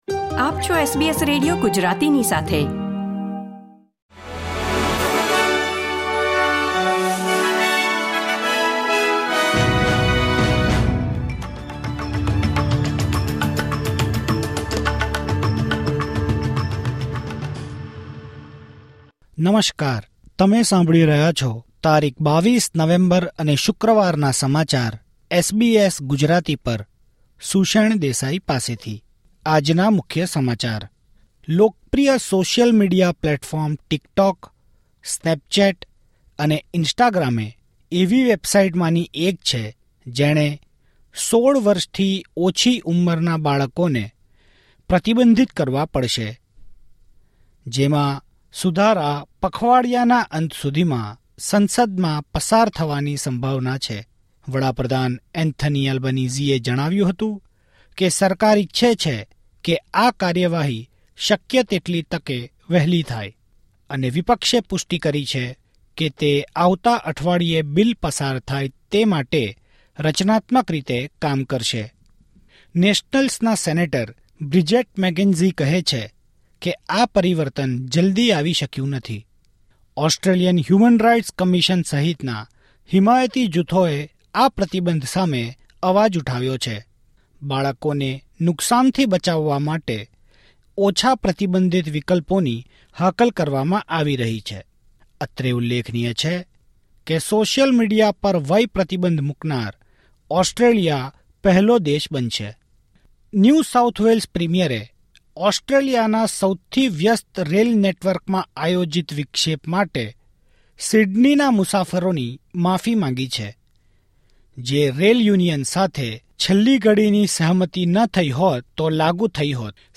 SBS Gujarati News Bulletin 22 November 2024